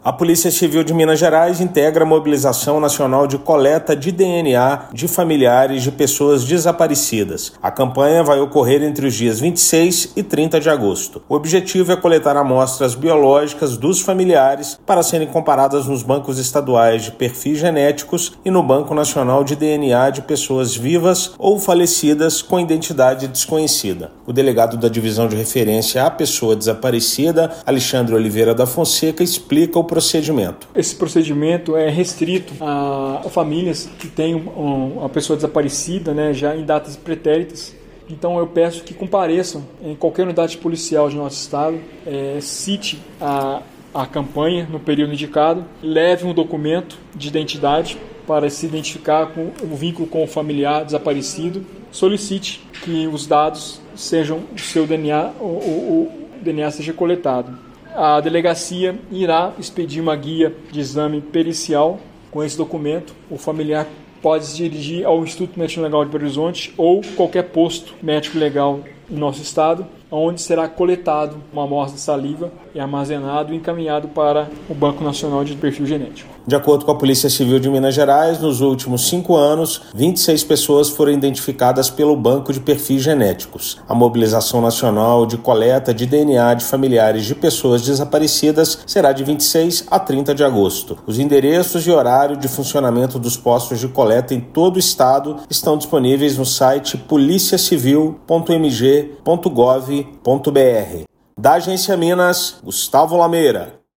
Objetivo é convidar familiares de pessoas desaparecidas a doarem amostra biológica para comparação com os Bancos Estaduais de Perfis Genéticos. Ouça matéria de rádio.